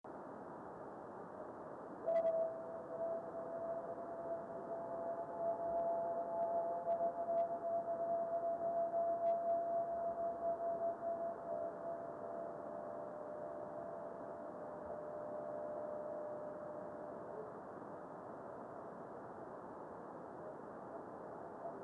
video and stereo sound:
Small meteor with moderate reflection.